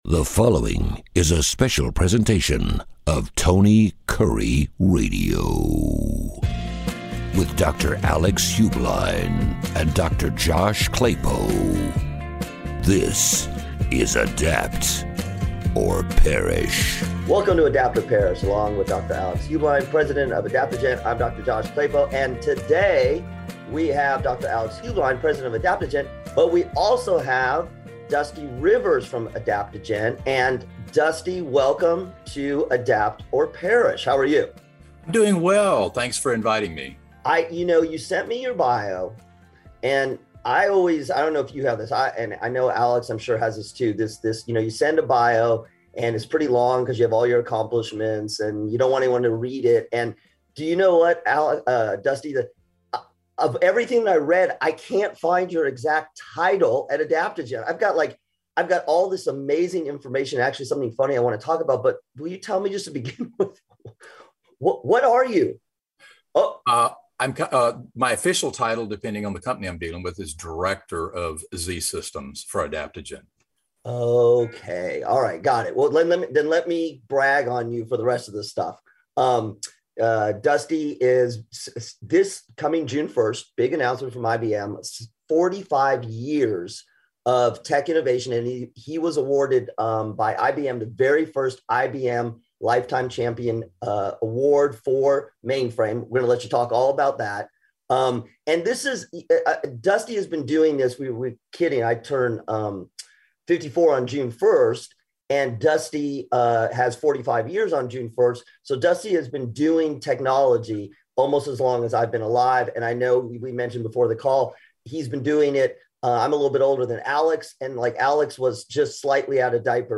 In this episode, the doctors have a very special interview